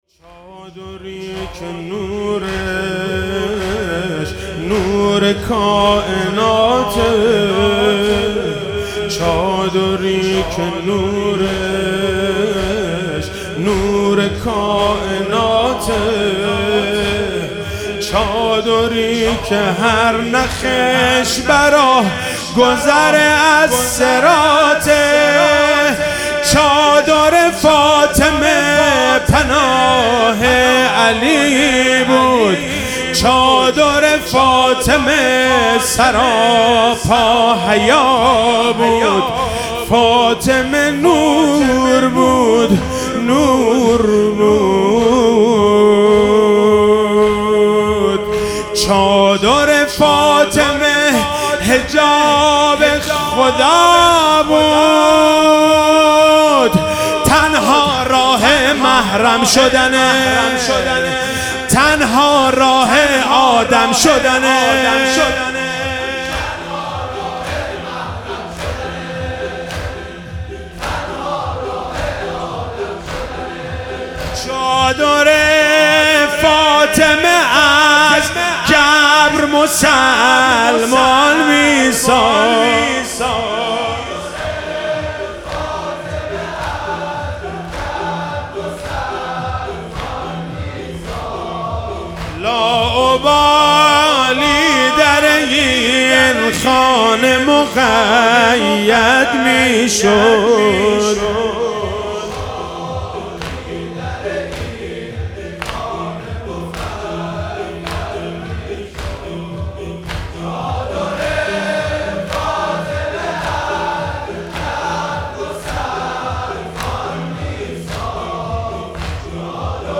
شب پنجم فاطمیه 98
زمینه - چادری که نورش نور کائناته